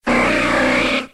Cri de Lamantine dans Pokémon X et Y.